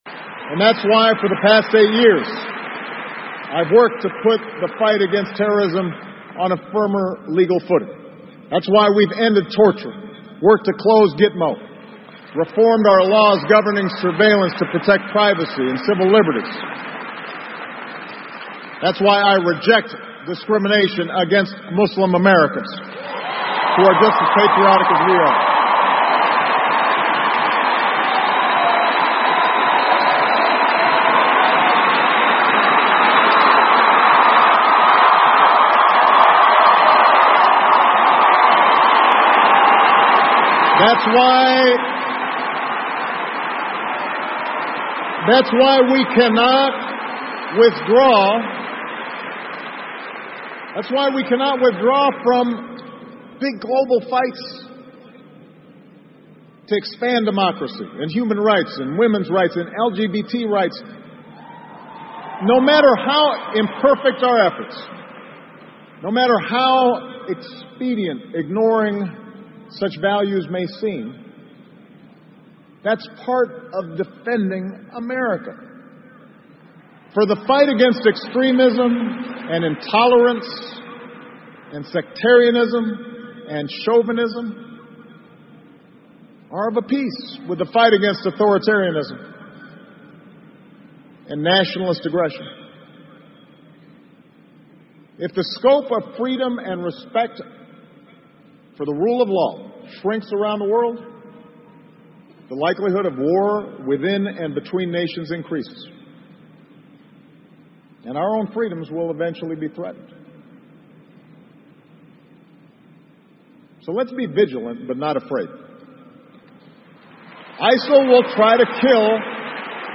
奥巴马每周电视讲话：美国总统奥巴马告别演讲(15) 听力文件下载—在线英语听力室